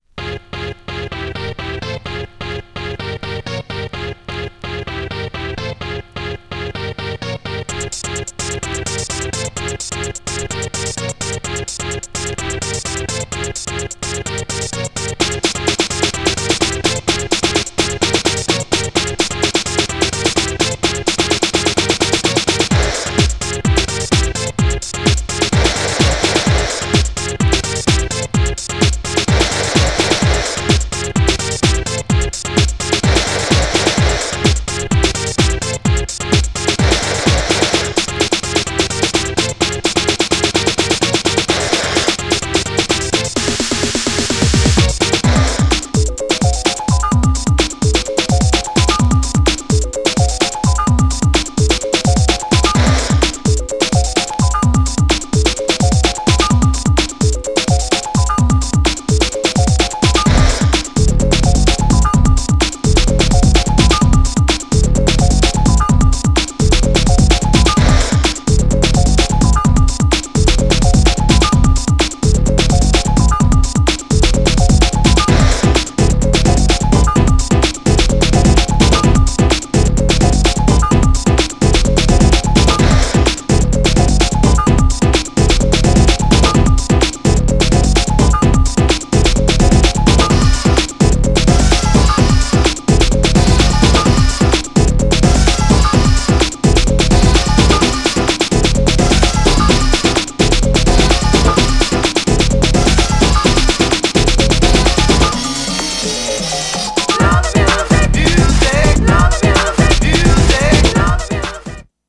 インダストリアルなムード漂うベースサウンドとテクノ〜HOUSEをクロスオーバーする鬼レア＆人気の１枚!!
BONUS MIX、全部カッコいい!!
GENRE House
BPM 121〜125BPM